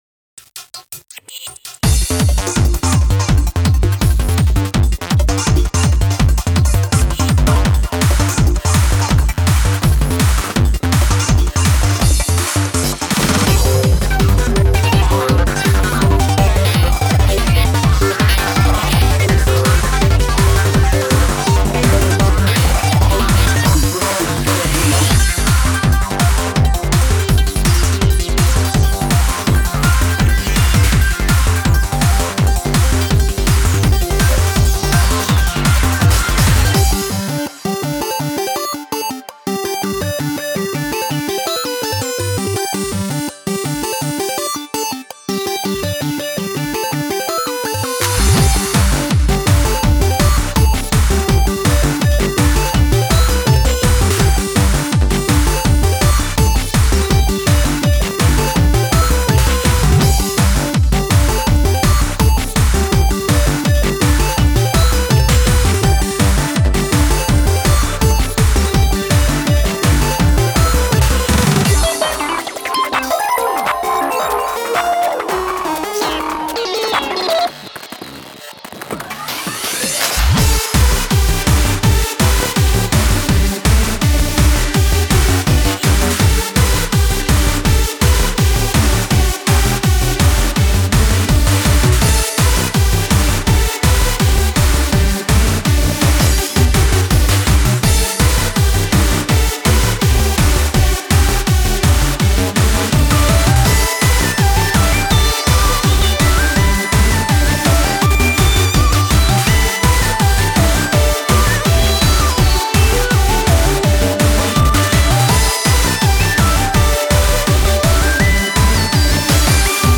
BPM83-165
Genre: TECH-TRONIKA.